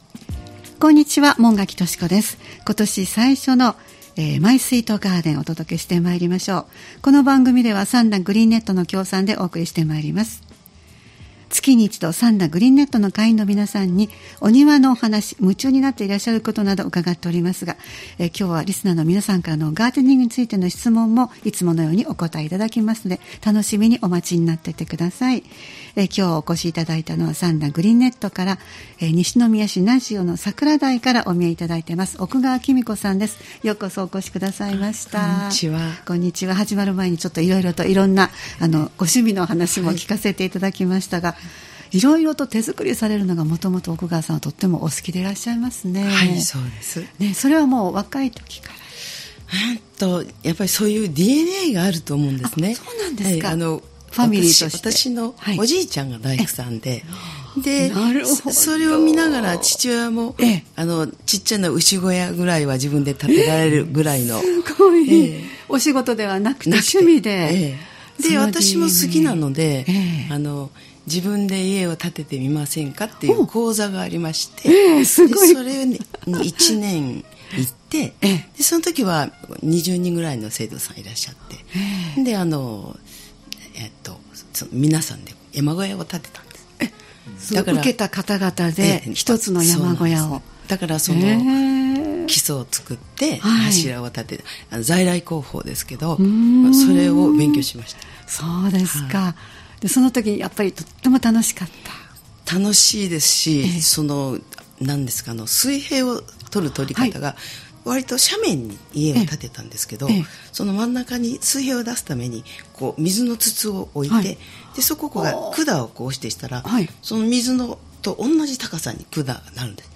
毎月第2火曜日は兵庫県三田市、神戸市北区、西宮市北部でオープンガーデンを開催されている三田グリーンネットの会員の方をスタジオにお迎えしてお庭の様子をお聞きする「マイスイートガーデン」（協賛：三田グリーンネット）をポッドキャスト配信しています（再生ボタン▶を押すと番組が始まります）